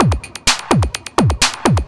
DS 127-BPM B1.wav